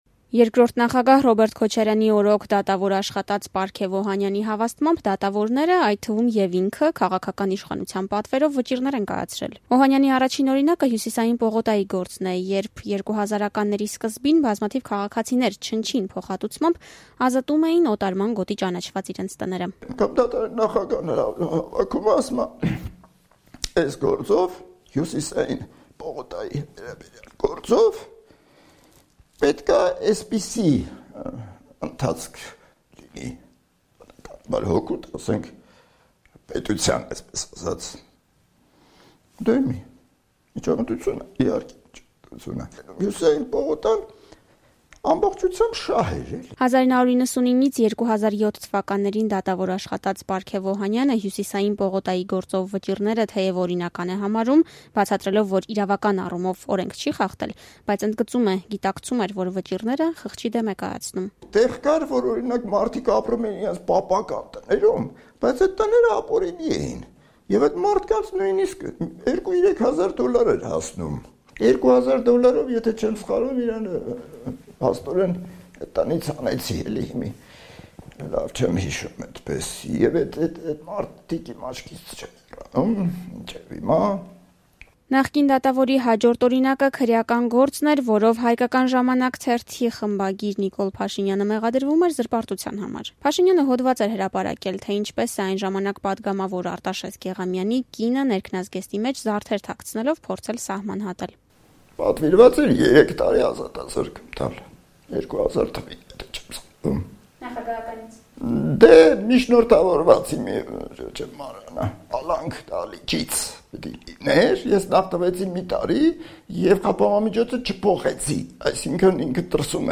Armenia -- Pargev Ohanian, Former Judge, speaks to RFE/RL, 14Sept., 2018